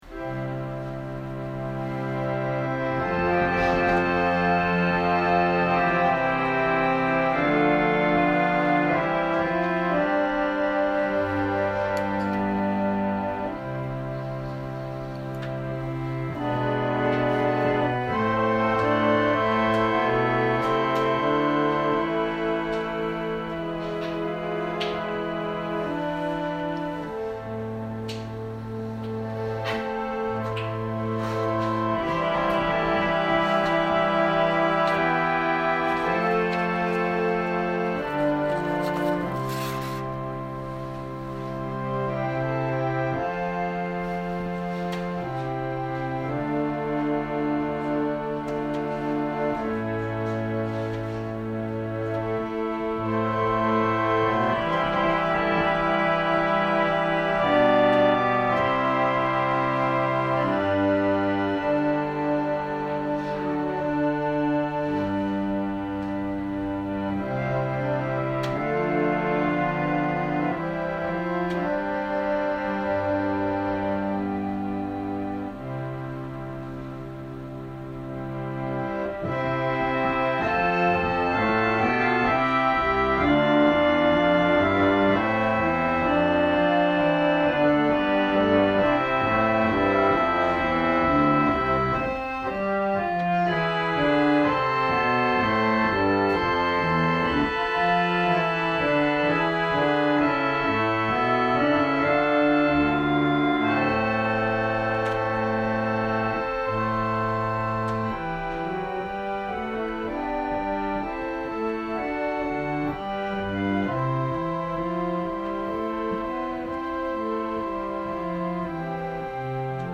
千間台教会。説教アーカイブ。